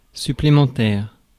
Ääntäminen
France: IPA: [sy.ple.mɑ̃.tɛʁ]